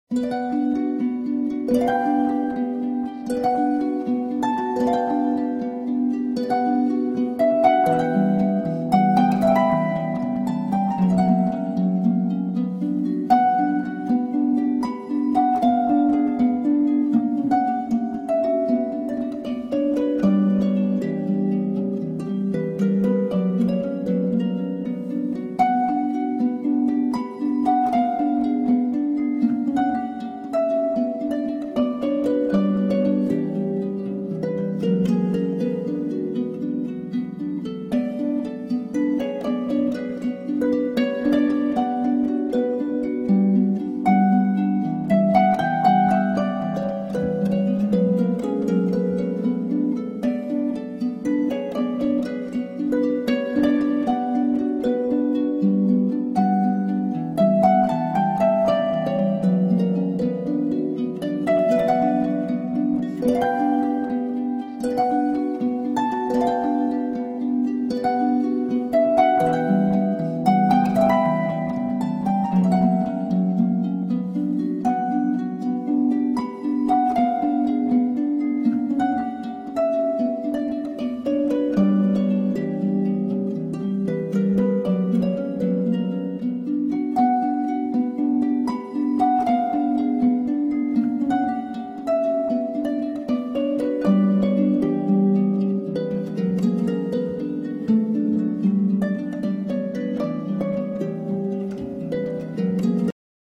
ЗАмечательный портрет...очень и музыка подходит....воздушно...нежно..и..и...и